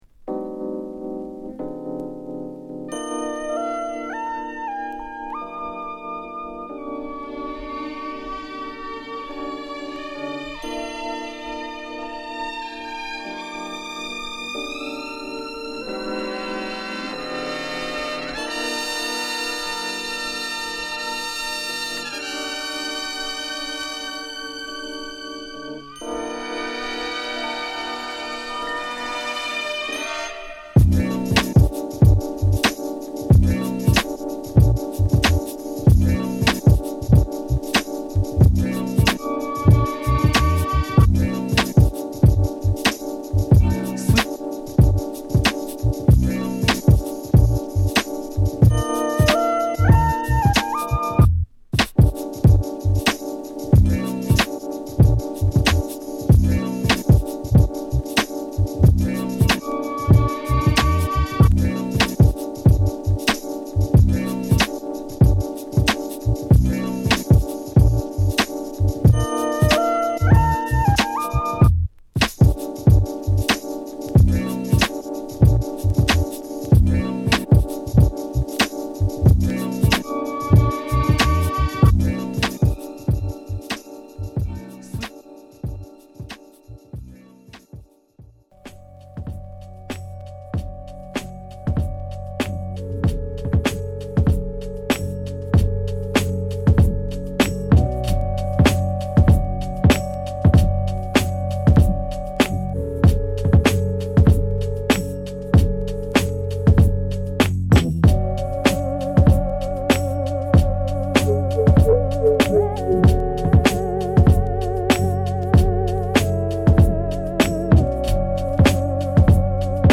タイトなドラムに柔らかな鍵盤プレイやサンプリングを散りばめたクオリティ高いビート陣。